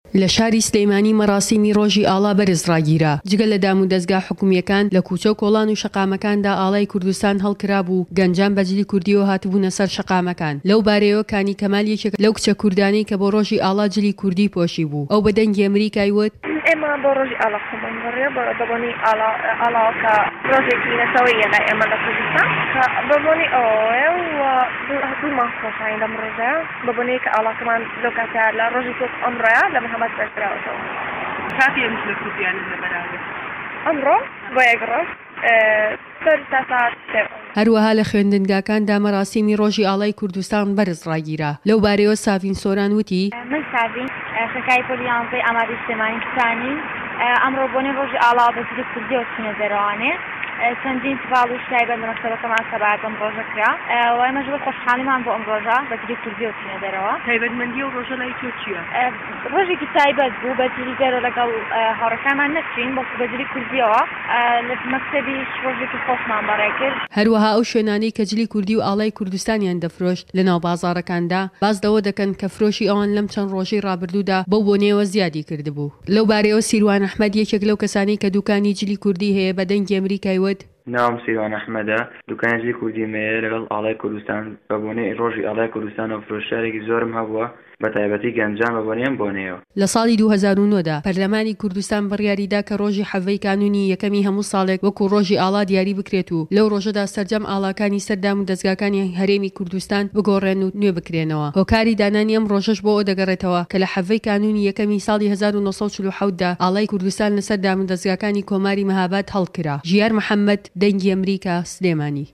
لە شاری سلێمانی مەراسیمی ڕۆژی ئاڵا بەرز ڕاگیرا جگە لەدامودەزگاكانی حكومیەكان لەكوچە و كۆڵان و شەقامەكاندا ئاڵای كوردستان هەڵكرابوو گەنجان بەجلی كوردییەوە هاتبونە سەر شەقامەكان